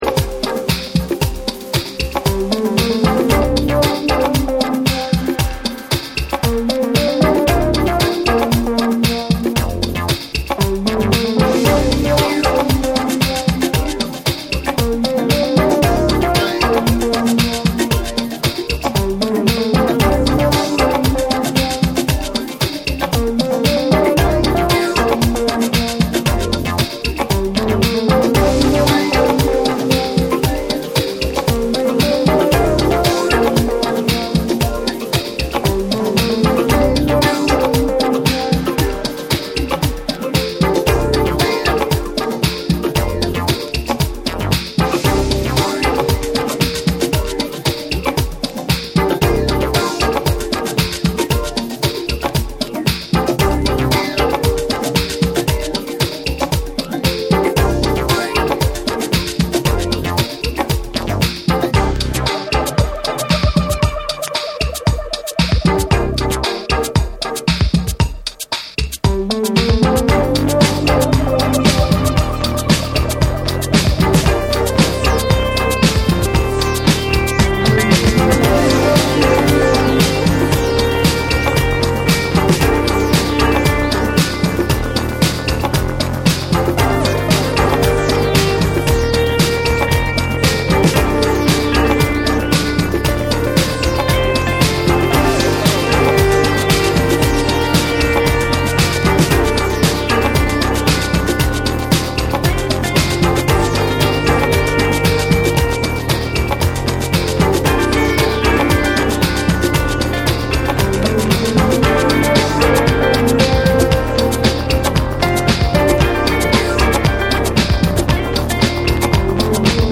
JAPANESE / TECHNO & HOUSE